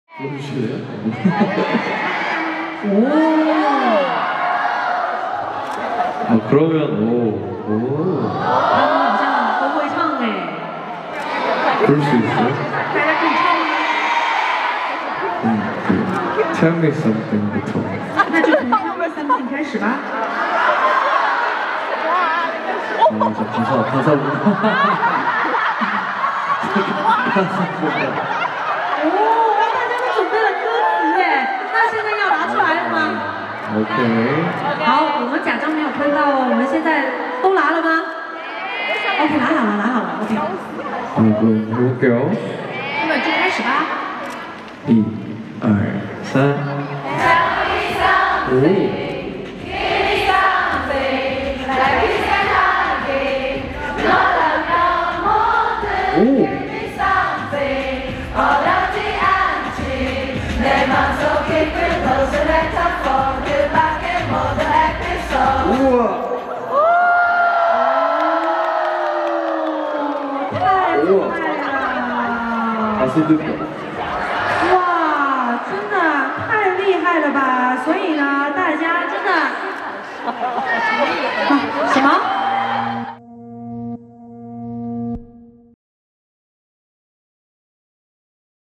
중국 팬들이 노래를 불러주자 찐으로 Sound Effects Free Download
Fan Meeting in Hangzhou